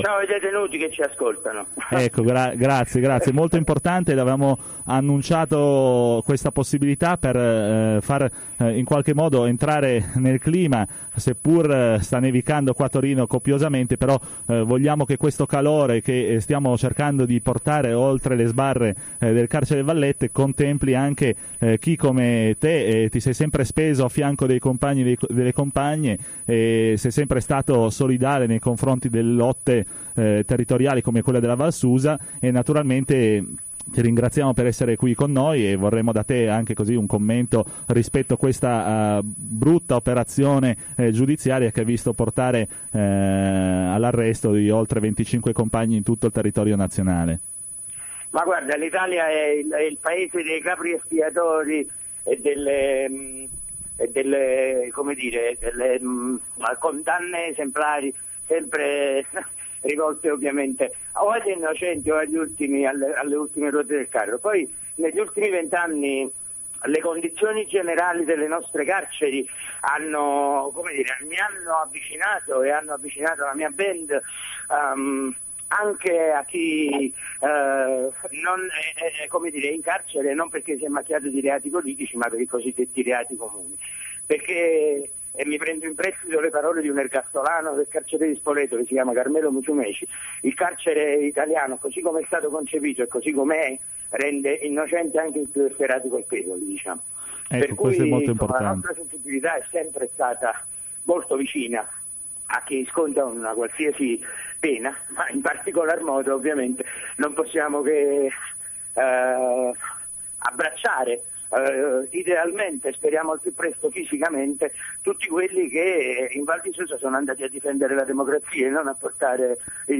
Per presentare l’appuntamento e portare il proprio personale saluto, i due Luca sono intervenuti alla trx “Onde Precarie” in onda ogni giovedì pomeriggio dalle h14.30 alle h 16.30 sulle libere frequenze di Radio Blackout
Il commento di Luca O’Zulù (99 Posse)